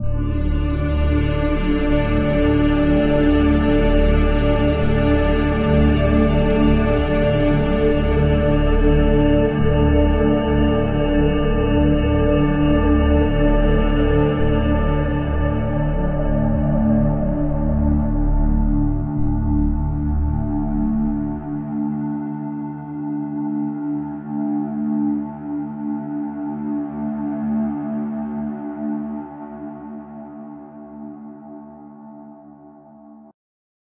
所有的声音都被捕获在独特的地方，如100年历史的破旧教堂和底特律的废弃工业建筑。
Tag: 拖车 管弦乐 电影 悬疑 雄蜂 戏剧 电影 减少 戏剧 电影 电影院 工业